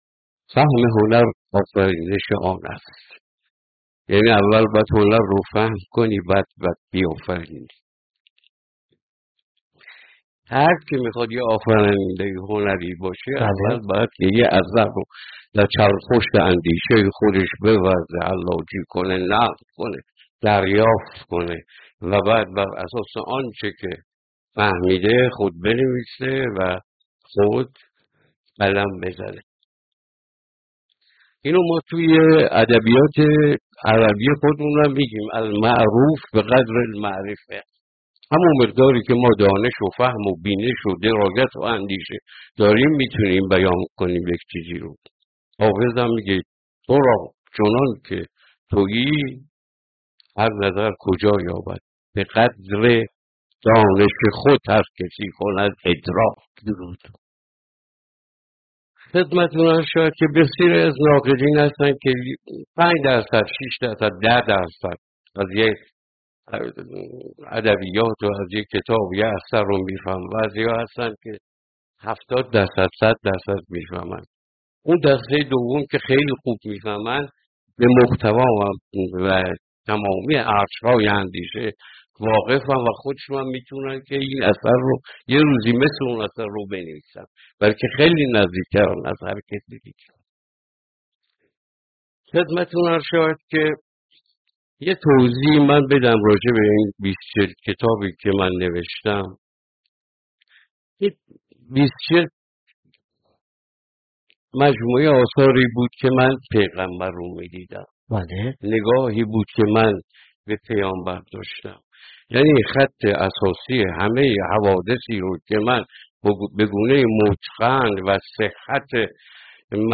گفت‌وگویی تعاملی و پژوهشی